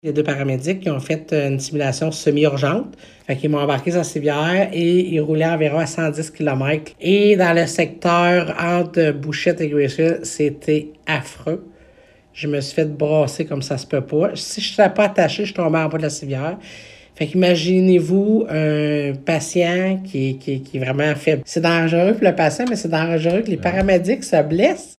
La préfète de la Vallée-de-la-Gatineau, Chantal Lamarche, a eu l’occasion d’accompagner des paramédics de la Vallée-de-la-Gatineau, vendredi soir, à l’occasion de leur quart de travail. En plus de lui permettre d’approfondir ses connaissances par rapport à ce métier, cette soirée a été l’occasion de constater les défis que représente la conduite d’un véhicule ambulancier sur la route 105, dont l’état ne cesse de se dégrader, aux dires de plusieurs. Voici les propos de la préfète Lamarche :